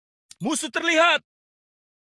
Efek Suara Musuh Terlihat
Kategori: Suara viral
Keterangan: Sound Effect "Musuh Terlihat" menjadi viral, sering digunakan dalam video gaming atau konten hiburan.
efek-suara-musuh-terlihat-id-www_tiengdong_com.mp3